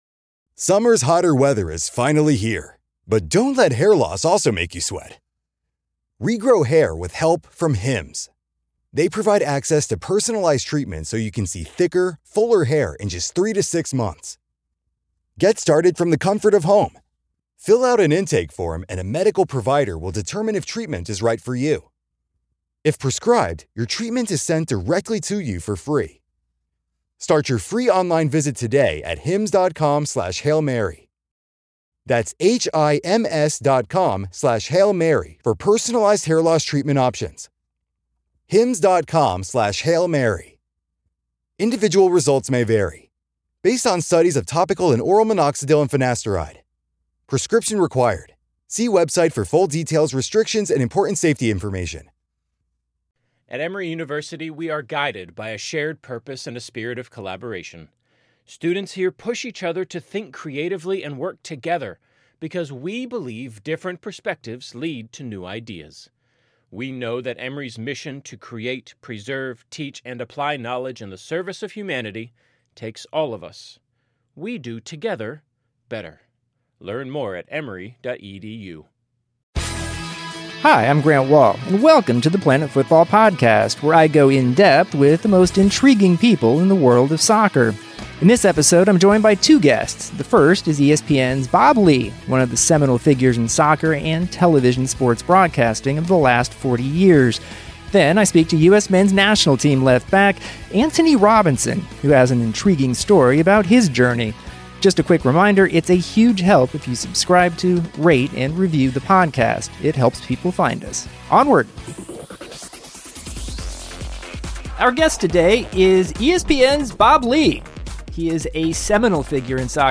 Interviews with ESPN's Bob Ley and USMNT's Antonee Robinson
Grant interviews ESPN's Bob Ley, one of the seminal soccer and TV sports broadcasters of the past 40 years. The focus here is on Ley's legendary experience in the soccer world, from the New York Cosmos to covering World Cups from 1982 to 2014, with some epic stories to tell along the way. Then Grant interviews Antonee Robinson, the 21-year-old left back who's playing for Wigan (on loan from Everton) and is trying to bring some stability to a position on the field that has dogged the U.S. for many years.